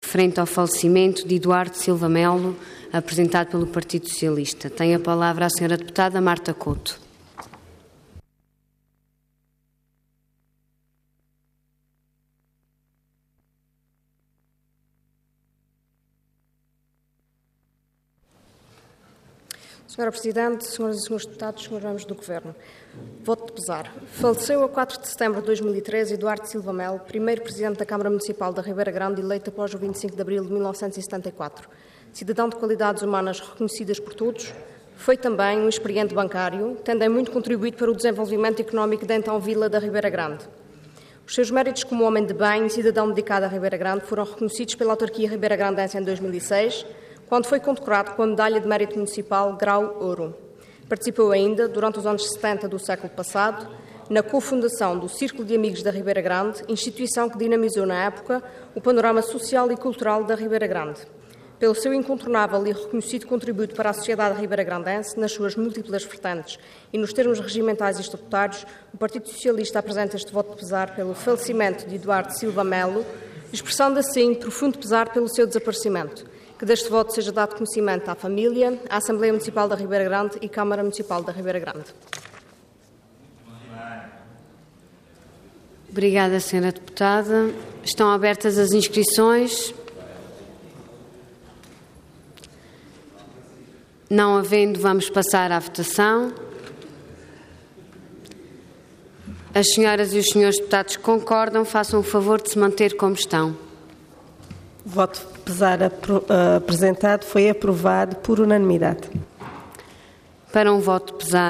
Intervenção Voto de Pesar Orador Marta Couto Cargo Deputada Entidade PS